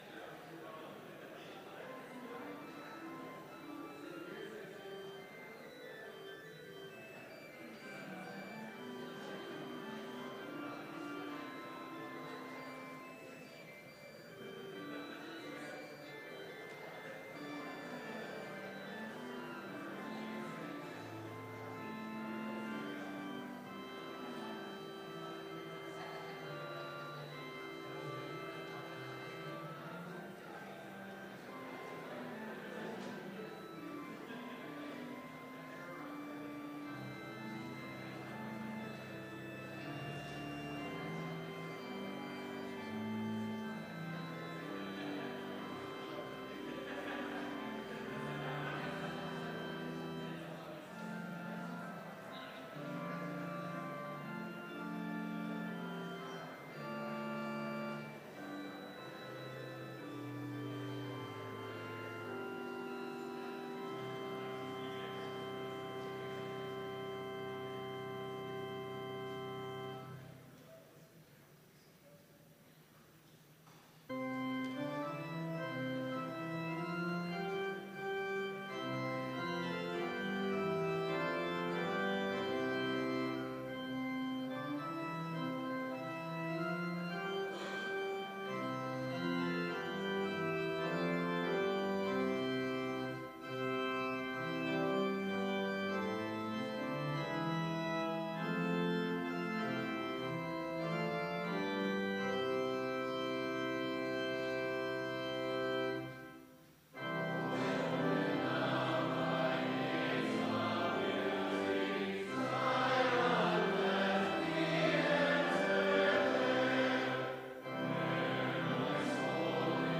Complete service audio for Chapel - October 23, 2019